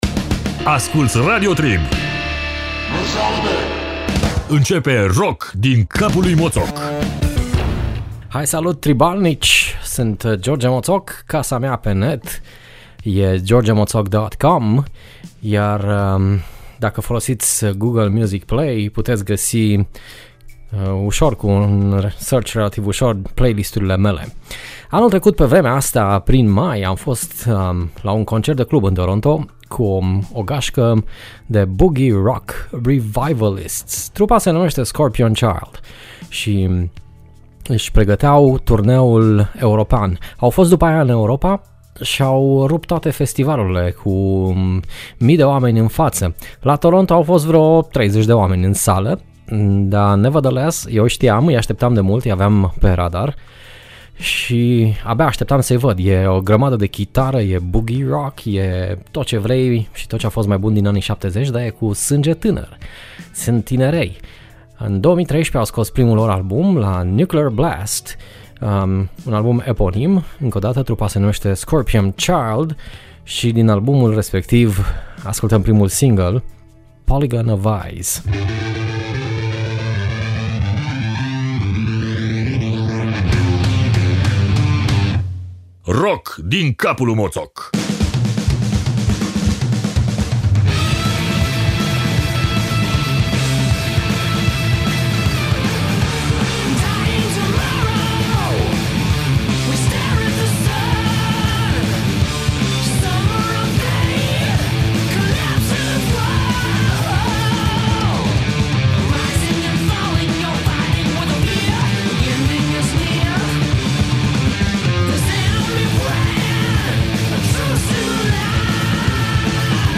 I-am vazut intr-un club din Toronto in iunie 2014, inaintea turneului lor european, unde aveau sa ravaseasca multimile cu brandul lor de high octane hard rock, blues rock si… scorpion rock.